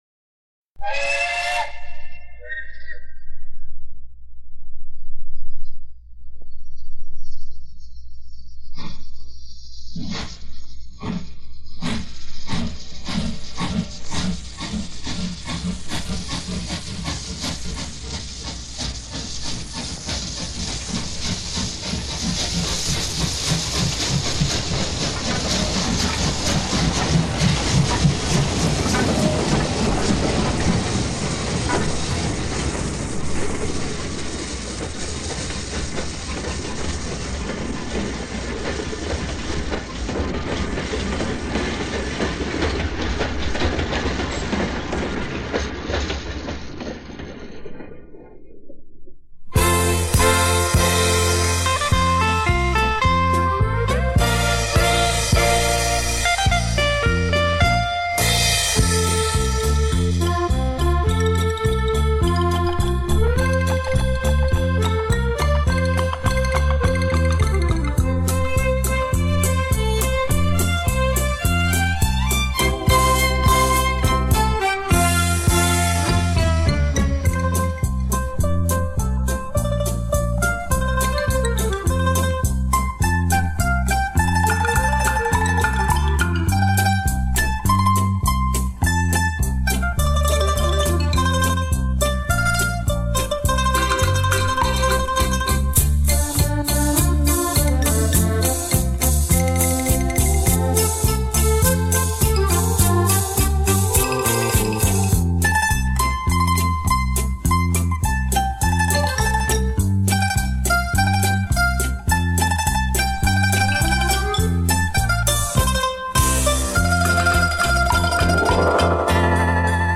（曼陀林、手风琴、小提琴）